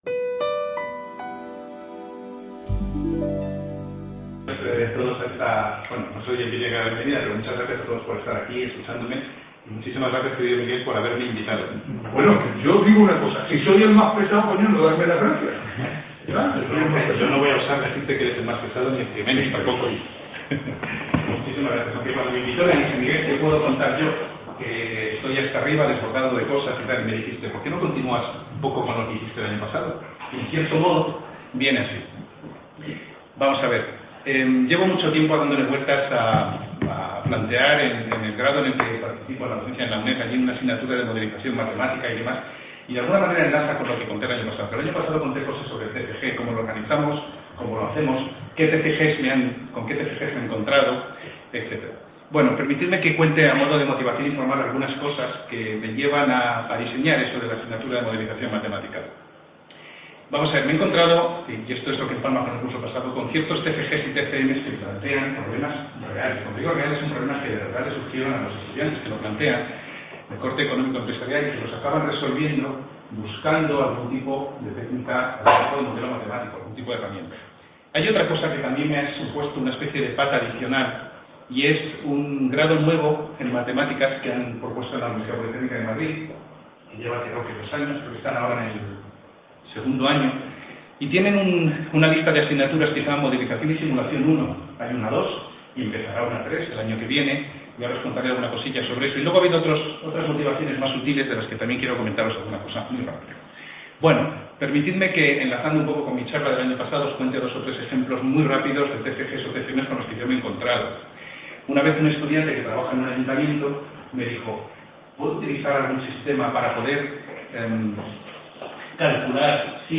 Ponencia S-6ª ¿Una asignatura de "Modelización…
Sextas Jornadas de Experiencias e Innovación Docente en Estadística y Matemáticas (eXIDO22)